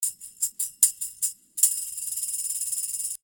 75 BPM tambourine (5 variations)
Tambourine loops in 5 variations playing in 75 bpm.